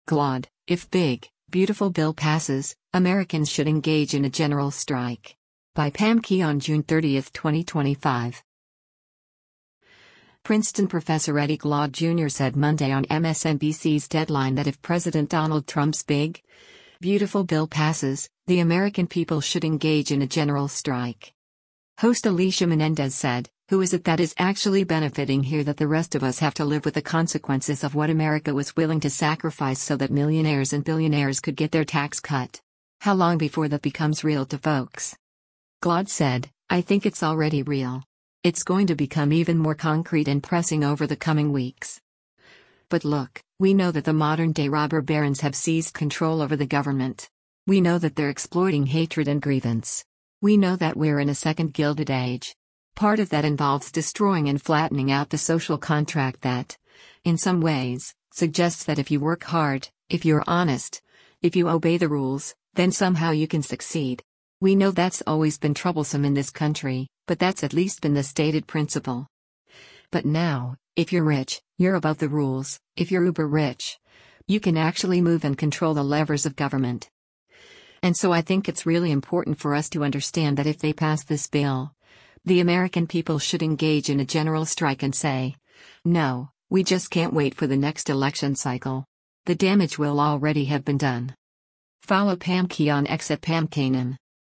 Princeton professor Eddie Glaude Jr. said Monday on MSNBC’s “Deadline” that if President Donald Trump’s big, beautiful bill passes, “the American people should engage in a general strike.”